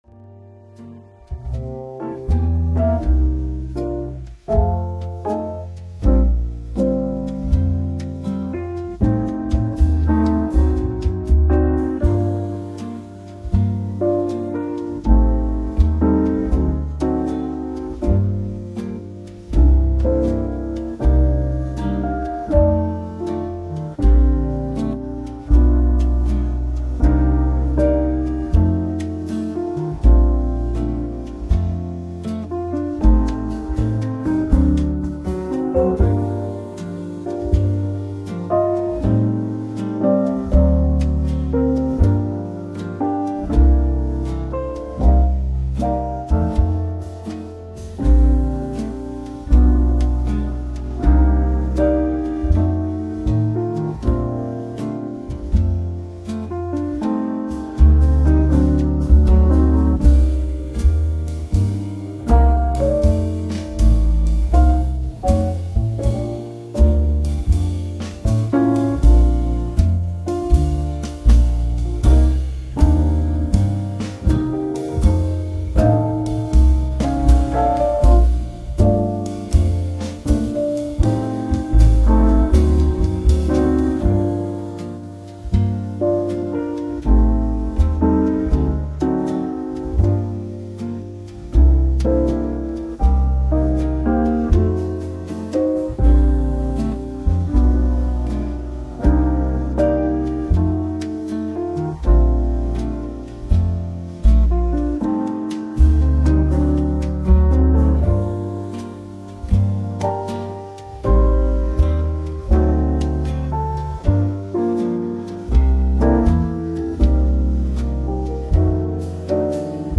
こちらがレコード音質加工前の原音です
Lo-Fi jazz